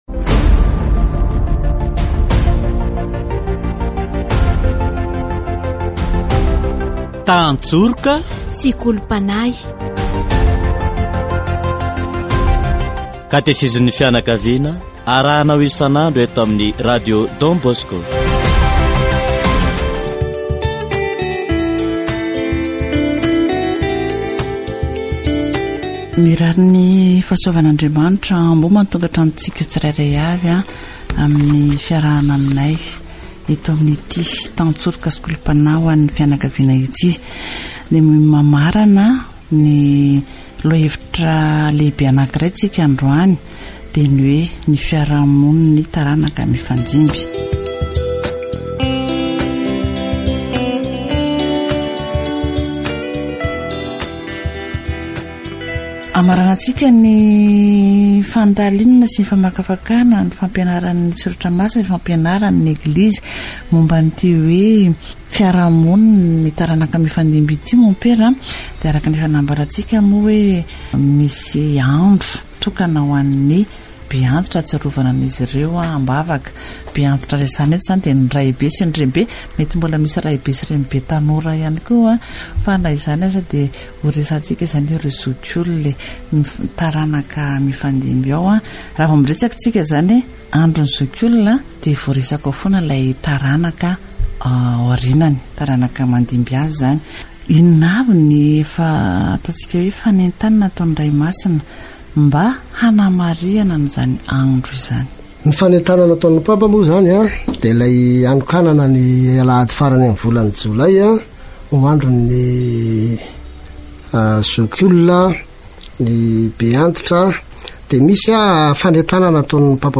Il portera du fruit dans sa vieillesse ; des mots d'espoir pour les jeunes et ceux qui vivent avec des personnes âgées... Catéchèse sur la consommation de drogues